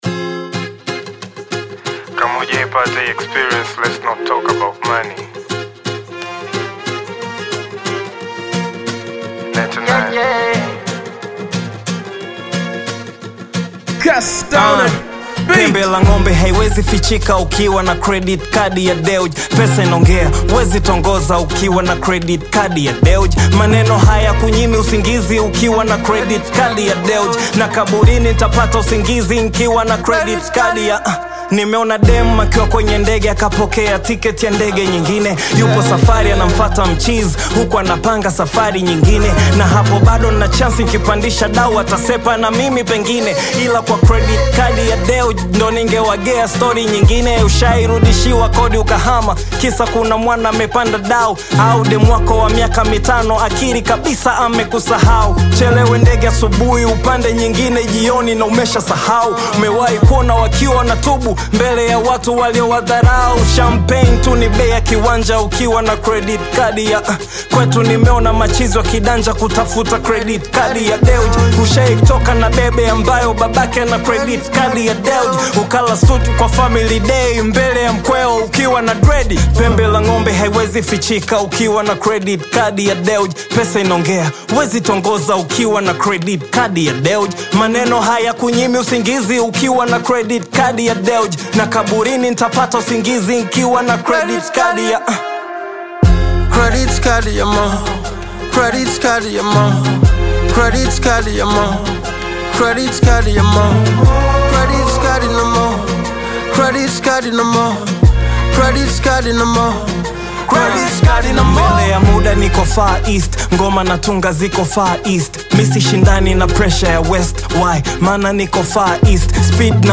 a high-energy collaboration
Bongo Flava